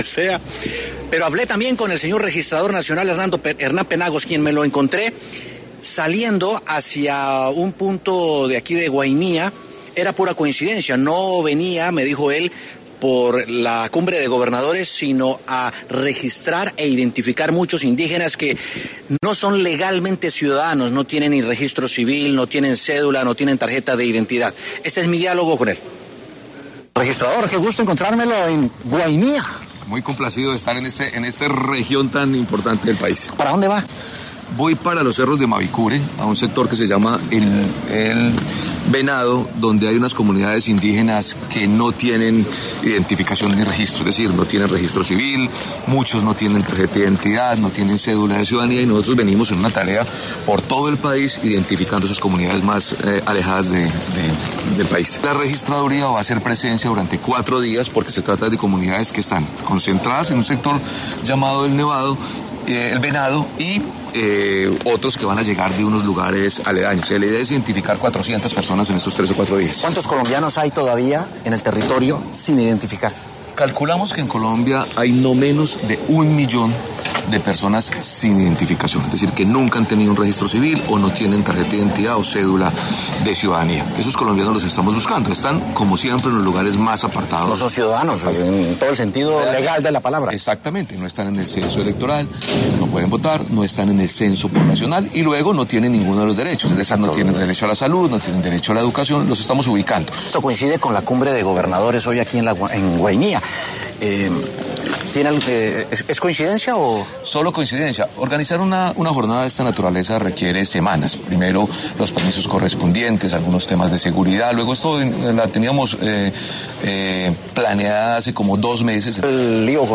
Hernán Penagos, registrador nacional, habló en W Sin Carreta sobre su labor en Guainía, en donde se dirigía a una comunidad indígena que se encuentra sin sus debidas identificaciones.
W Sin Carreta se encuentra desde el departamento de Guaina en el marco de la Cumbre de Gobernadores y allí se dialogó con uno de los principales entes del país: la Registraduría.